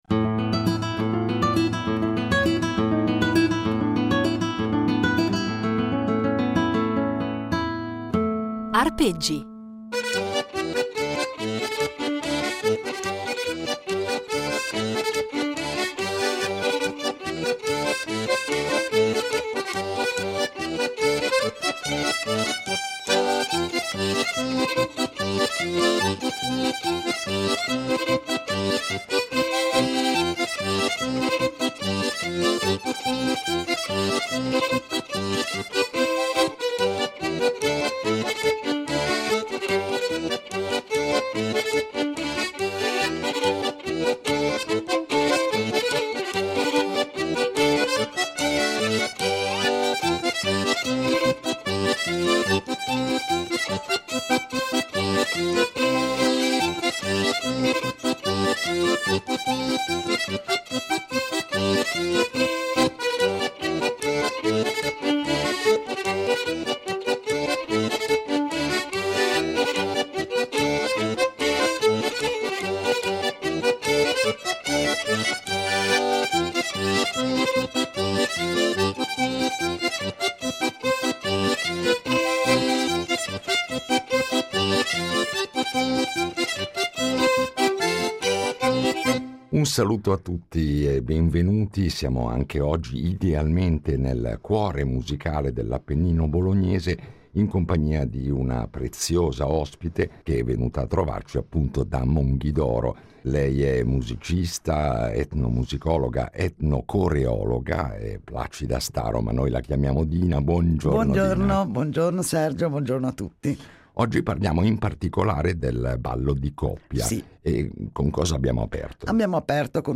Un itinerario sonoro ricco di materiale inedito, registrato sul campo e negli anni da lei stessa, e illuminato da esempi che ci propone dal vivo, con la sua voce e il suo violino e accompagnata dalle percussioni di